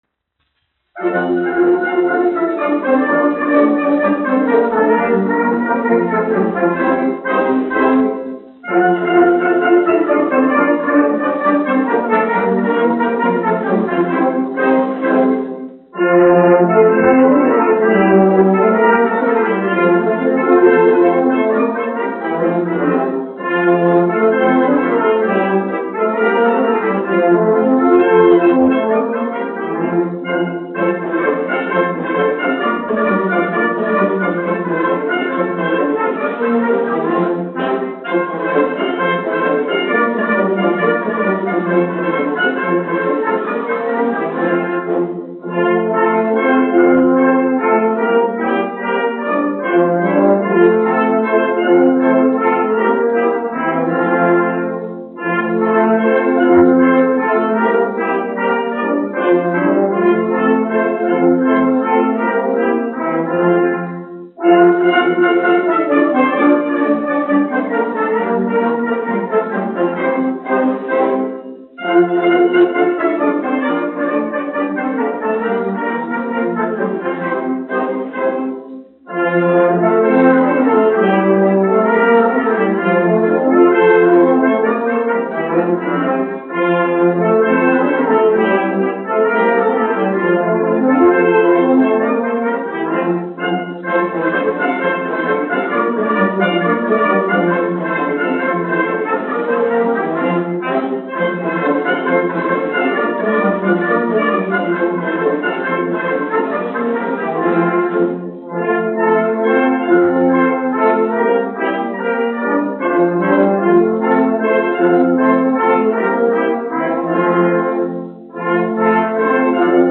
6. Rīgas kājnieku pulka orķestris, izpildītājs
1 skpl. : analogs, 78 apgr/min, mono ; 25 cm
Latviešu tautas dejas
Pūtēju orķestra mūzika
Skaņuplate